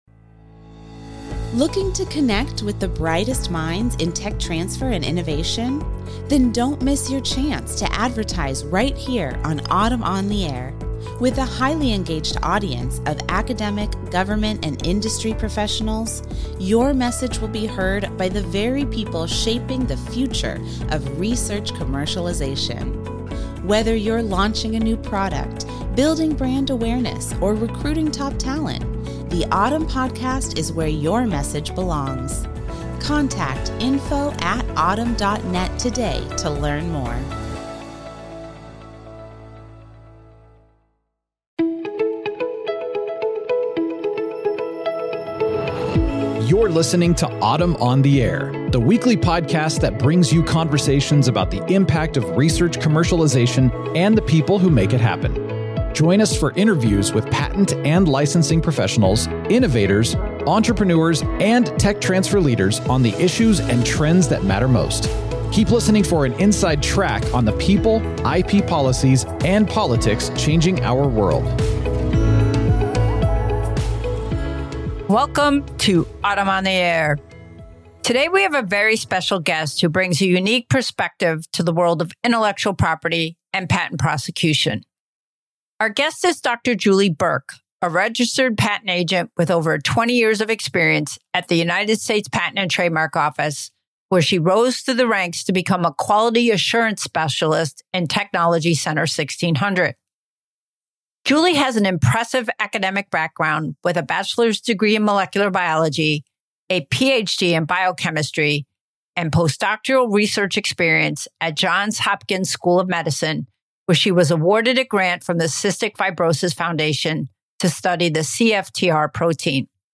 AUTM on the AIR is the weekly podcast that brings you conversations about the impact of research commercialization and the people who make it happen. Join us for interviews with patent and licensing professionals, innovators, entrepreneurs, and tech transfer leaders on the issues and trends that matter most.